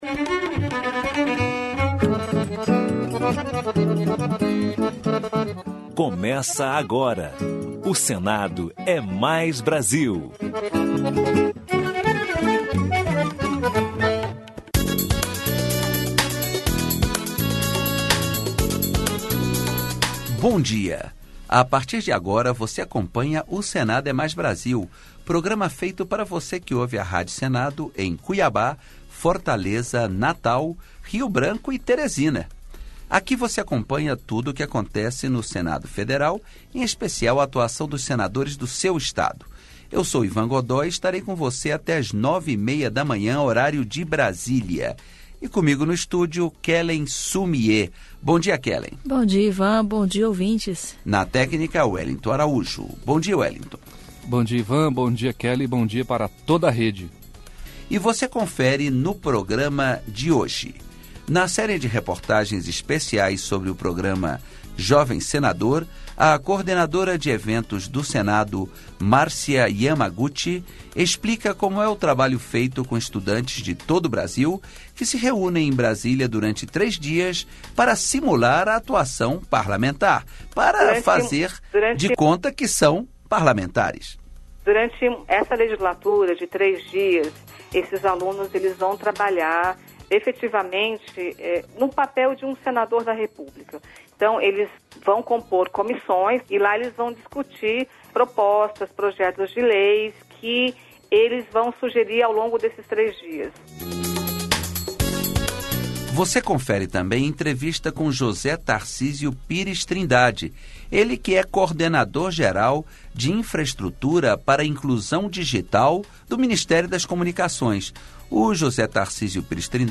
Notícias: giro pelos estados Entrevista Especial